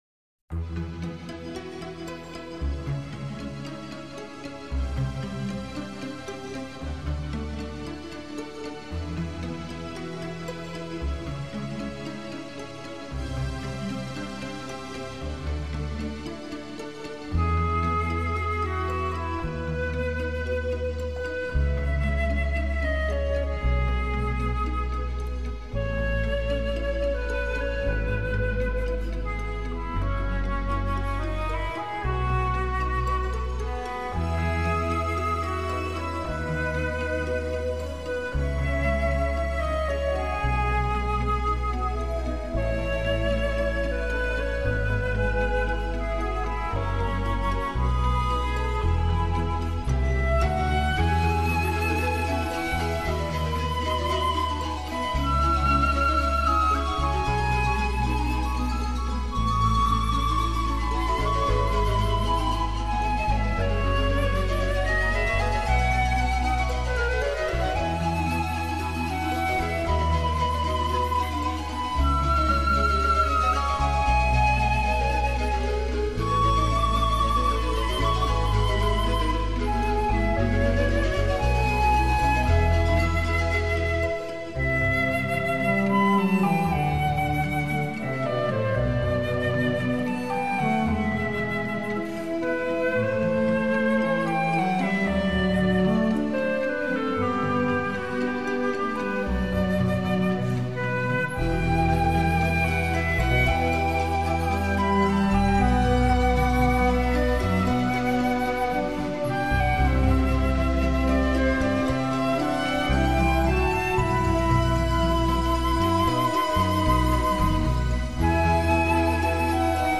来自北爱尔兰贝尔法斯特的一位长笛演奏家